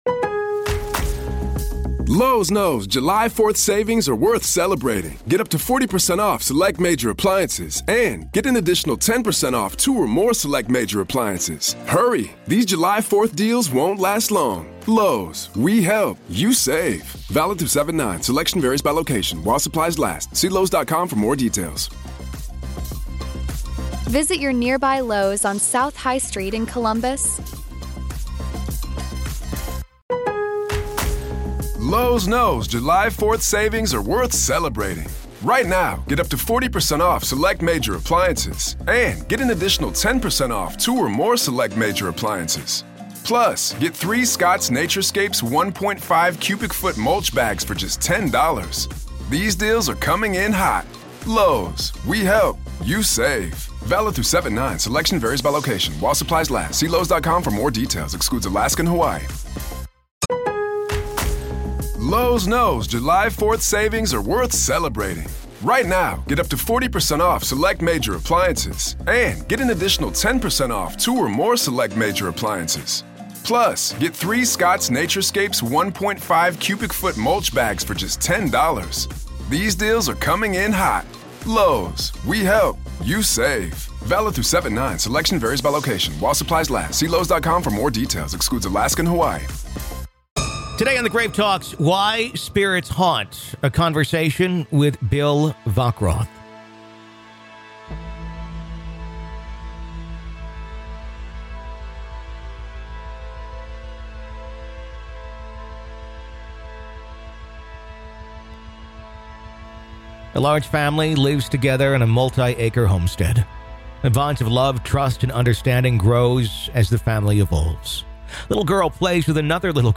In part two of our interview